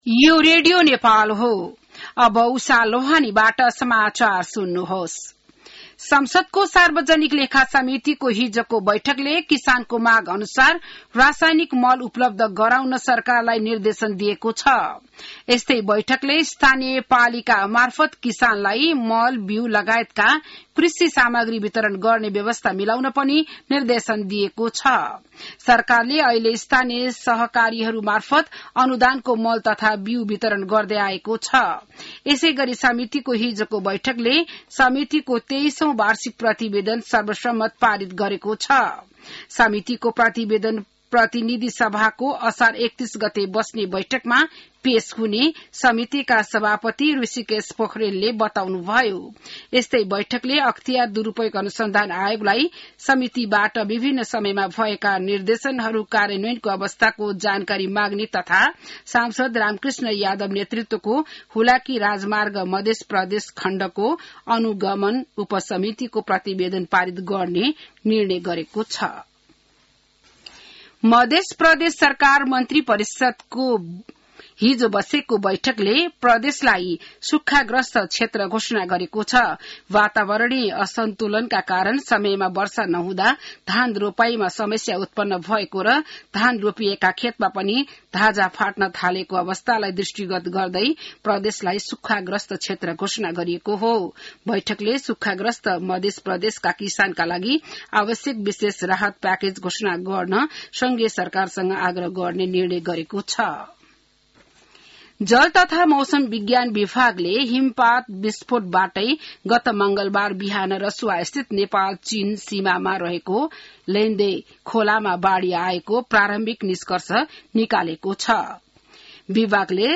बिहान १० बजेको नेपाली समाचार : २७ असार , २०८२